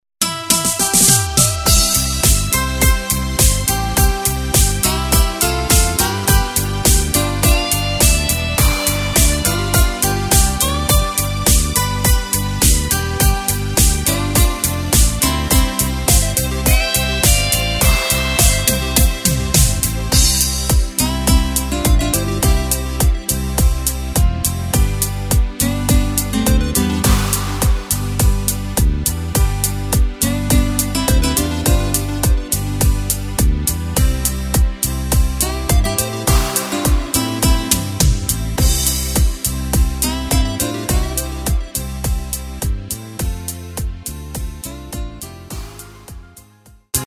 Takt: 4/4 Tempo: 104.00 Tonart: C
Discofox